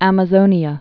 (ămə-zōnē-ə)